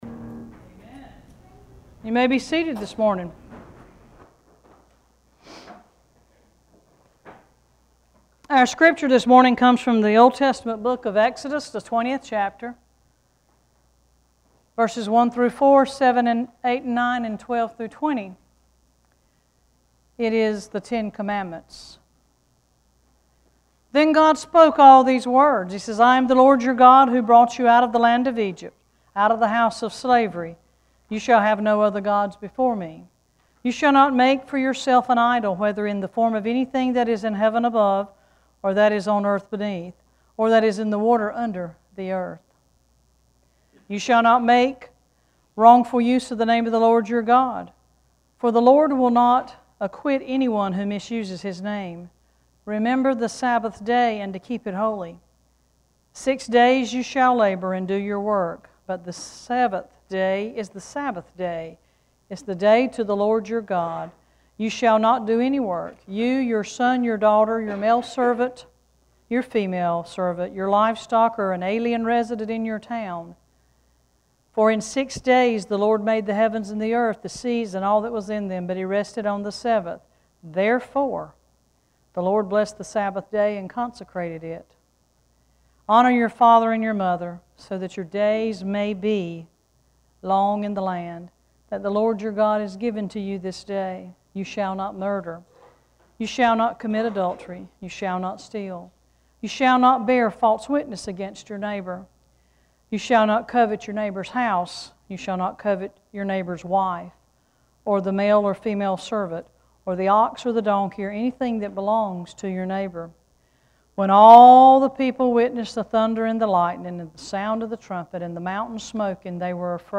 Worship Service 10-5-14: What More Can You Say
10-5scripture.mp3